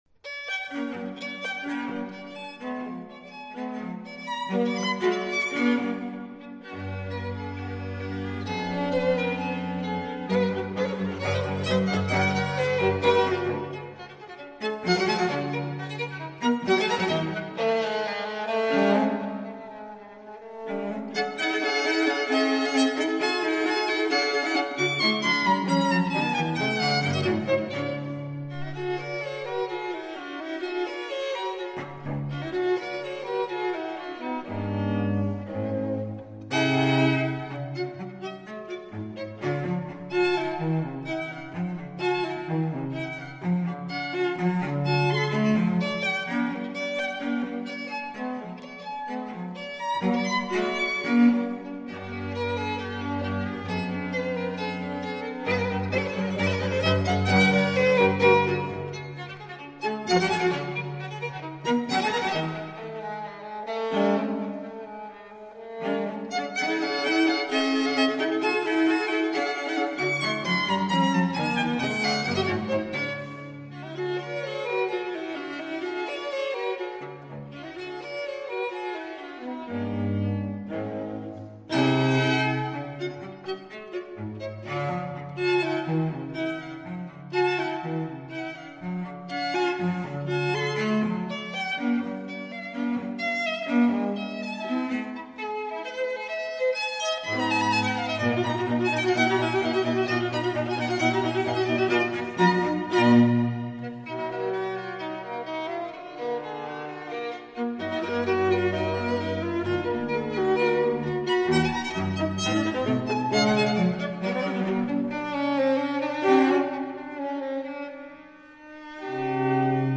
a lively, bumptious  Allegro scherzando